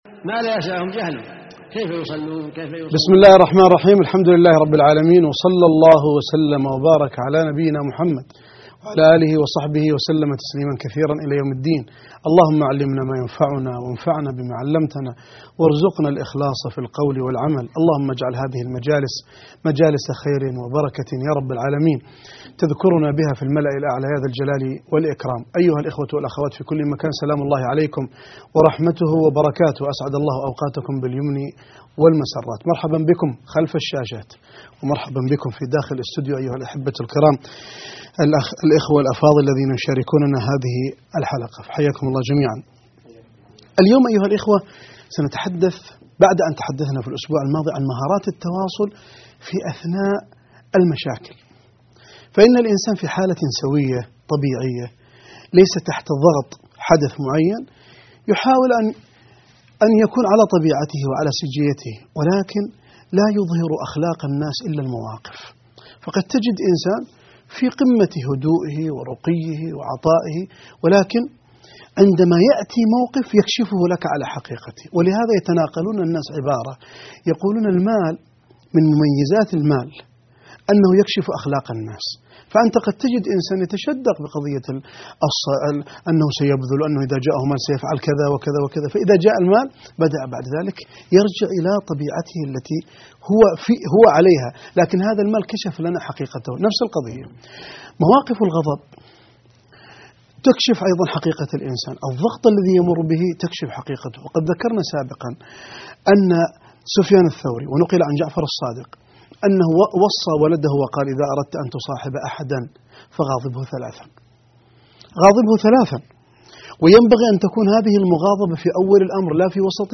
الدرس 8 _ علاقتنا بوالدينا